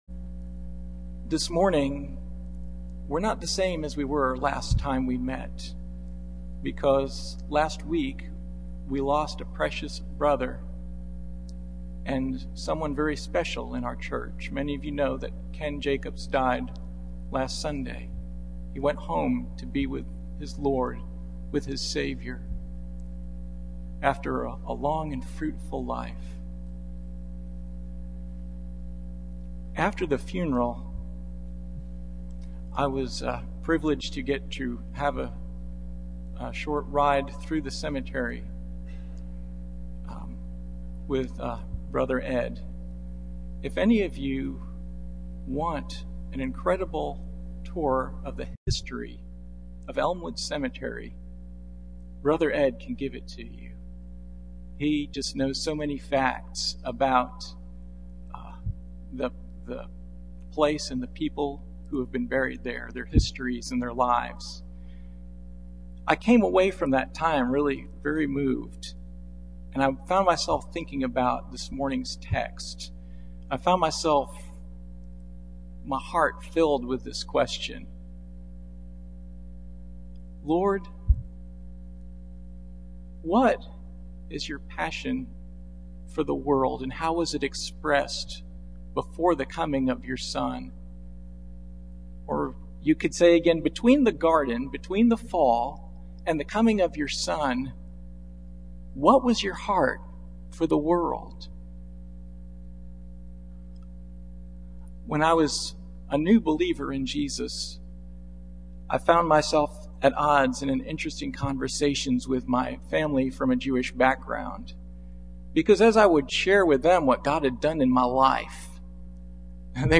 Passage: Genesis 12:1-11 Service Type: Sunday Morning